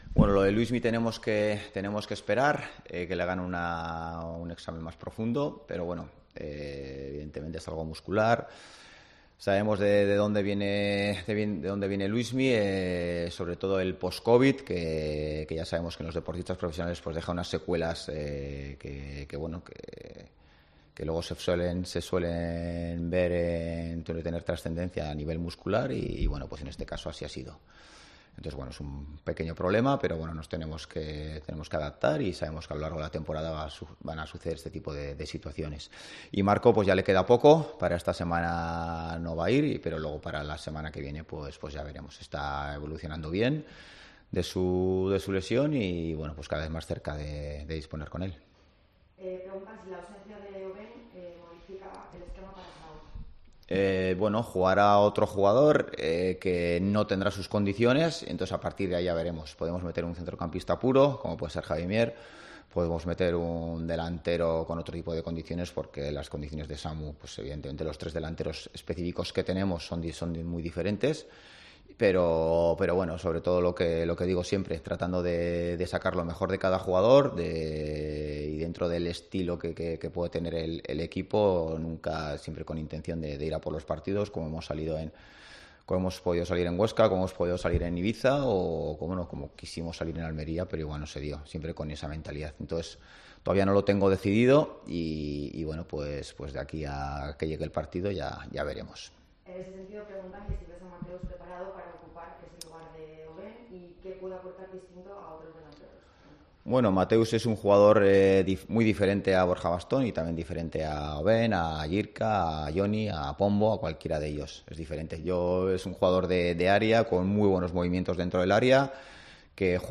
Rueda de prensa Ziganda (previa Zaragoza-Oviedo)